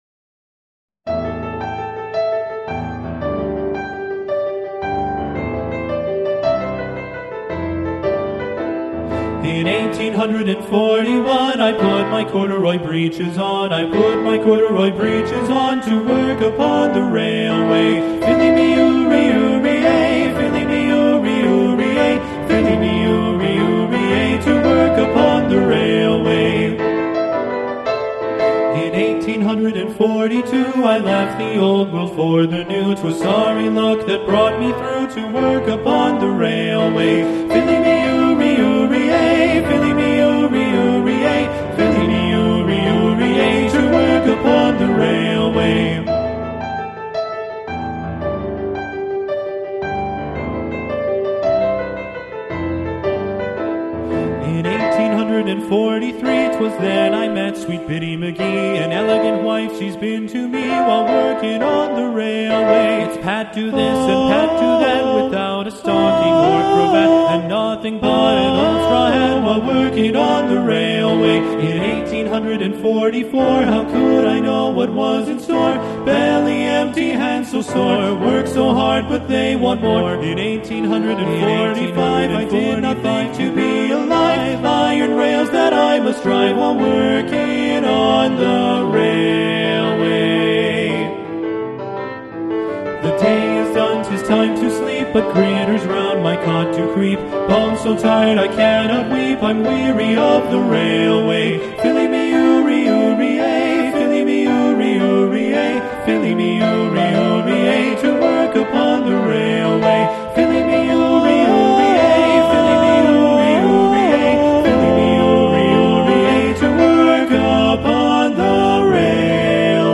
Voicing: TB and Piano